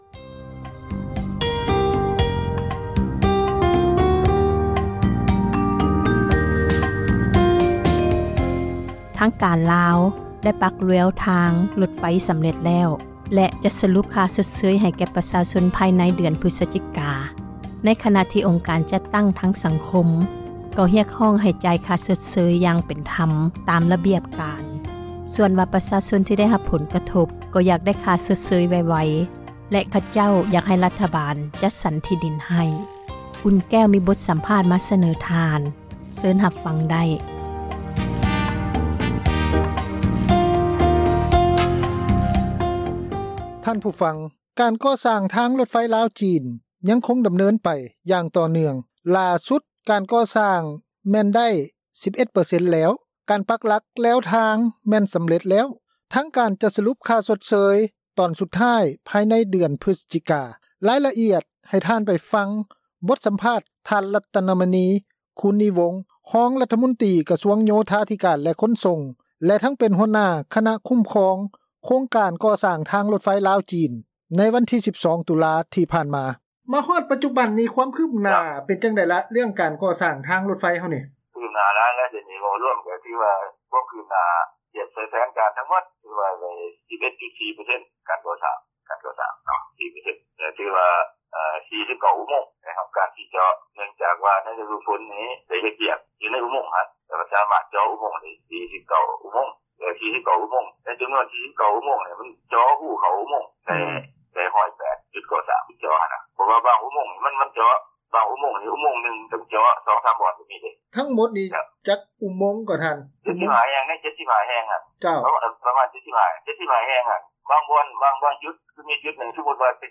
ລາຍລະອຽດ ໃຫ້ທ່ານຟັງ ບົດສຳພາດ ທ່ານ ລັດຕະນະມະນີ ຄູນນີວົງ ຮອງຣັຖມົນຕຼີ ກະຊວງ ໂຍທາທິການ ແລະ ຂົນສົ່ງ ແລະ ທັງເປັນຫົວໜ້າ ຄະນະຄຸ້ມຄອງ ໂຄງການ ກໍ່ສ້າງທາງຣົດໄຟ ລາວ-ຈີນ ໃນວັນທີ 12 ຕຸລາ ຜ່ານມາ.